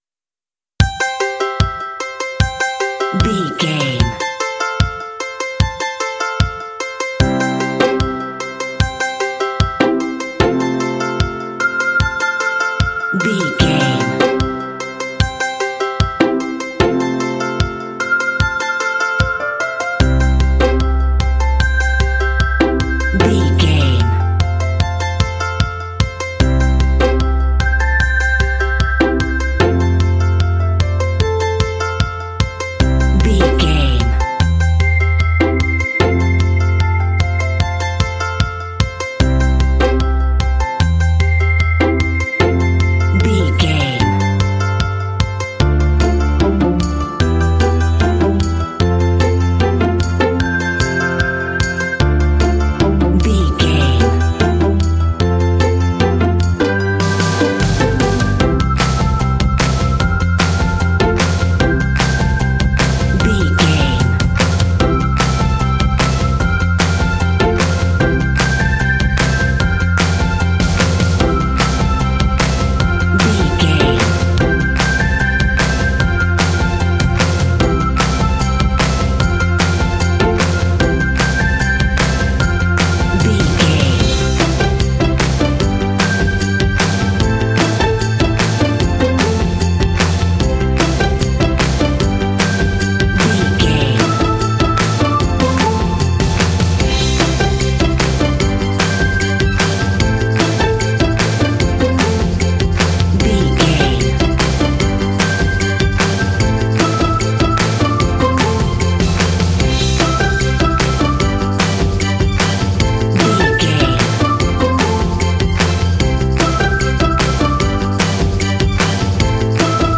Ionian/Major
bouncy
bright
repetitive
driving
energetic
happy
drums
strings
acoustic guitar
piano
contemporary underscore